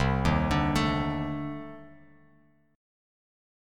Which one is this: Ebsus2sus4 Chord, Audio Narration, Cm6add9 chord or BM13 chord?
Cm6add9 chord